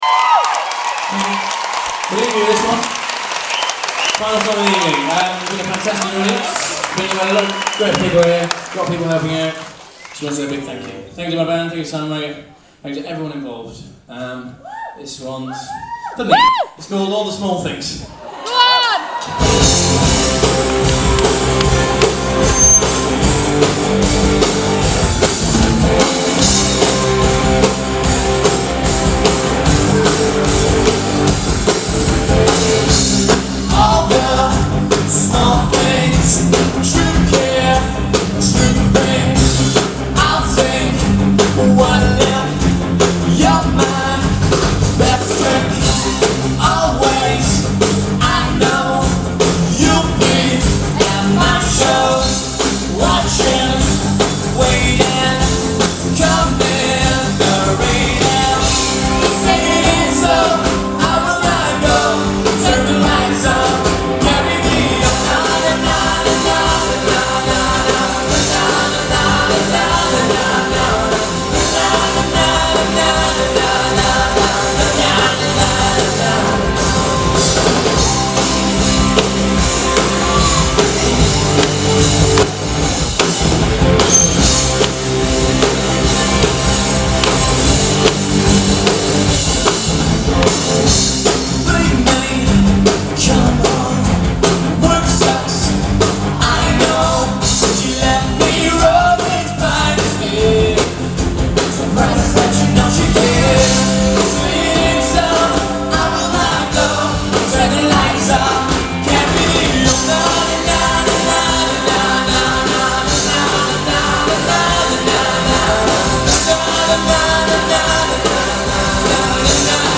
Band Set